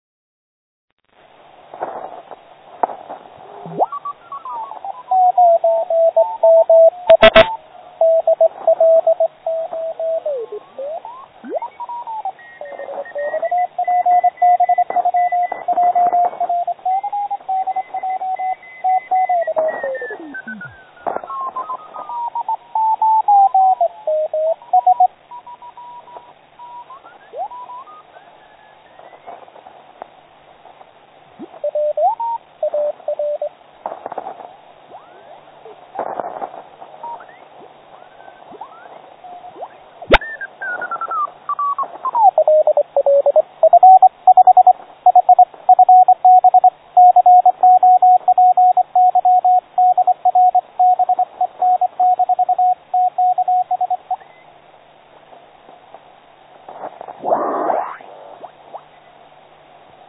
O to více zarážející je poslech na přímosměši s fázově potlačeným druhým záznějem.